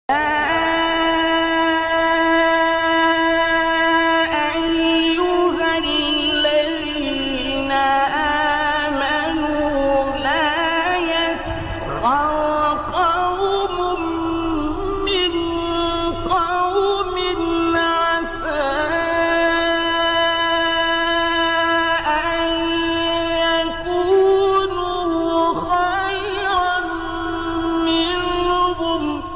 تلاوة_خاشعة#بصوت_الشيخ_عبدالباسط_عبدالصمد